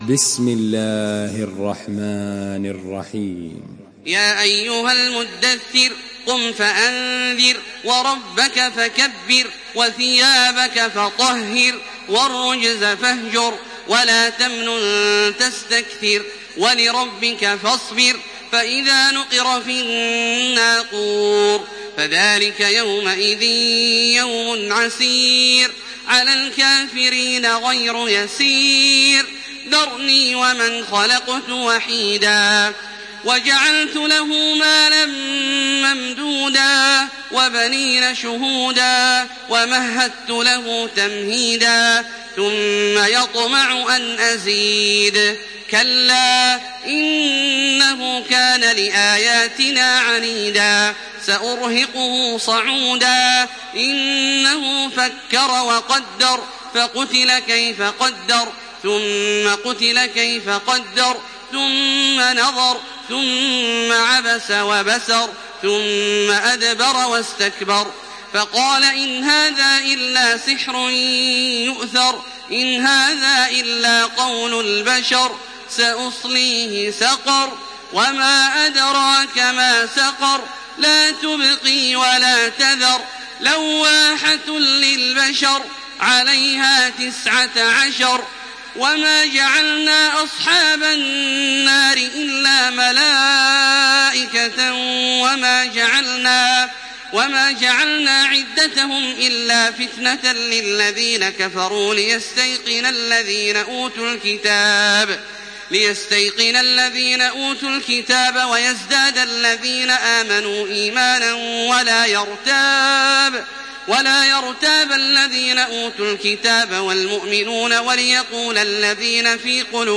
Makkah Taraweeh 1428
Murattal